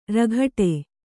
♪ raghaṭe